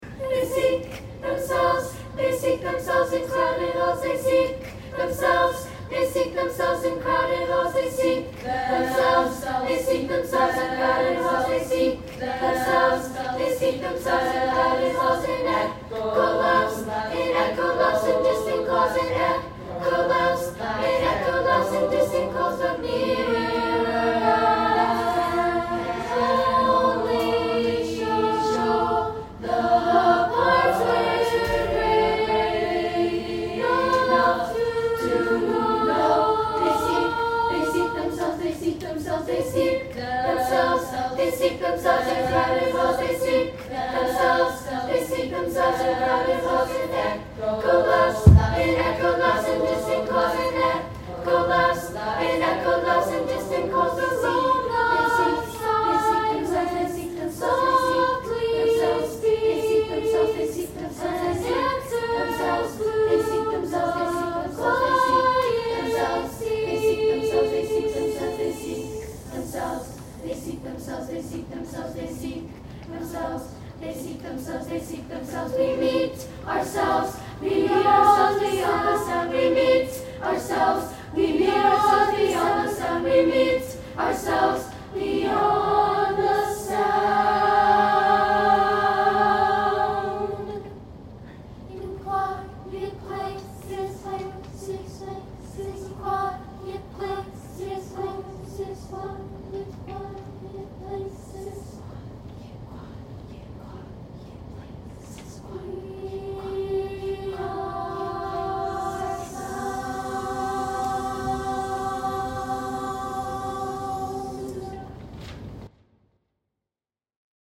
SSA – unaccompanied